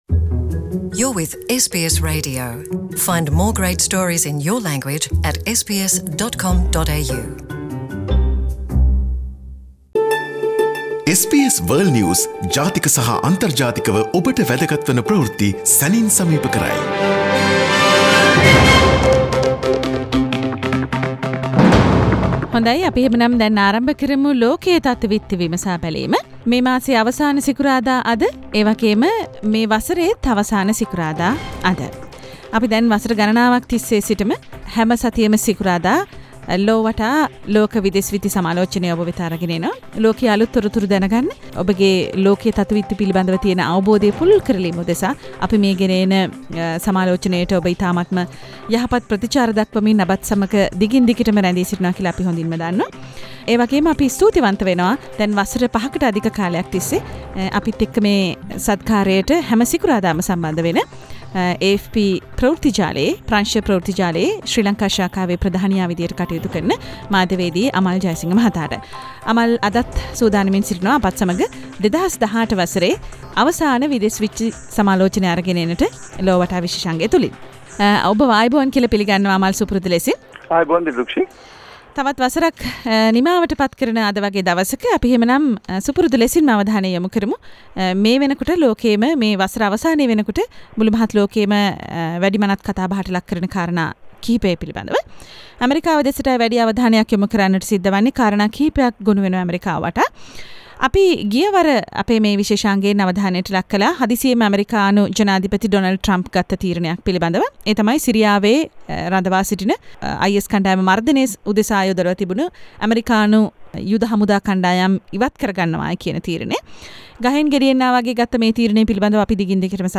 This week world news highlights from SBS Sinhalese radio Source: SBS Sinhala